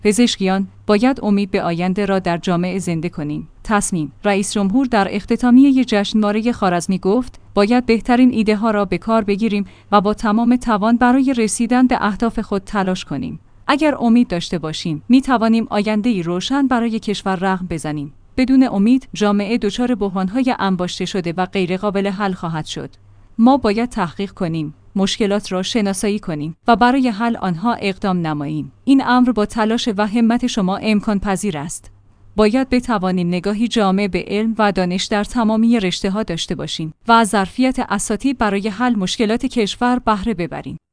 تسنیم/ رئیس‌جمهور در اختتامیه جشنواره خوارزمی گفت:باید بهترین ایده‌ها را به کار بگیریم و با تمام توان برای رسیدن به اهداف خود تلاش کنیم. اگر امید داشته باشیم، می‌توانیم آینده‌ای روشن برای کشور رقم بزنیم.